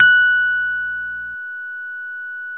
RHODES CL0KR.wav